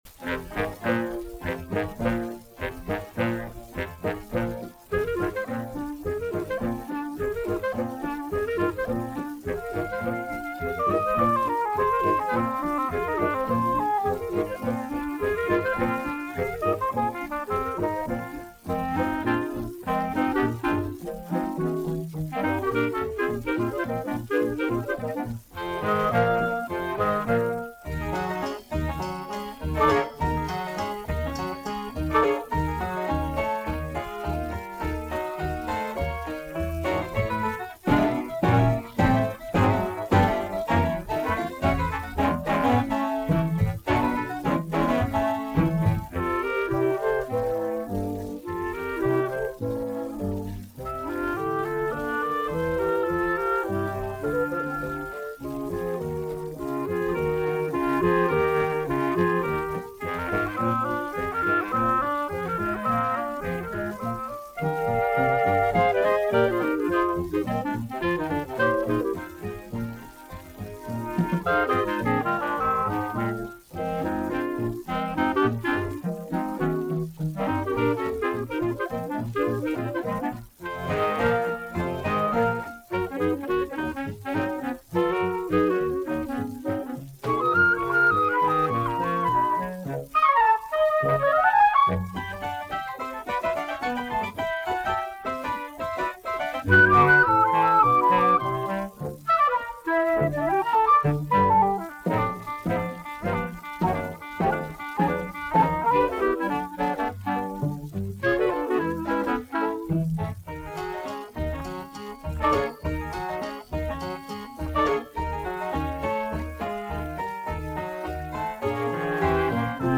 an octet